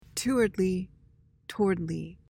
PRONUNCIATION:
(TO-uhrd-lee, TORD-lee)